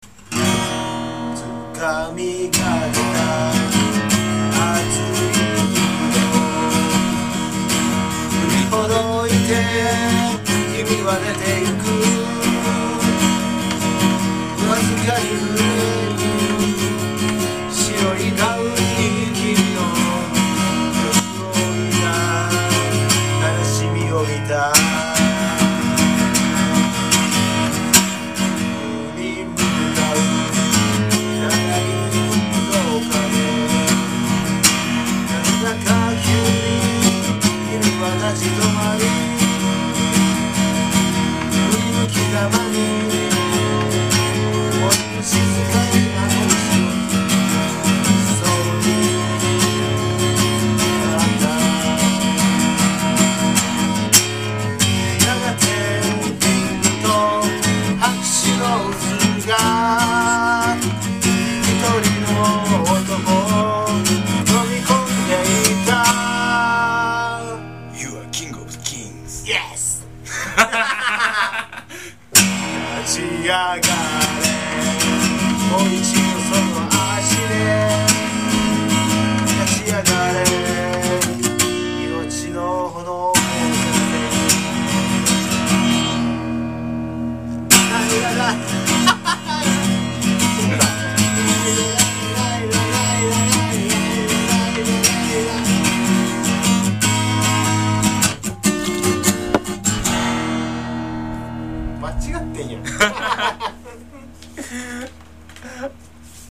握りつぶしたような切ない歌声が、老婆の中に入って、留まって、何かを隆起させた。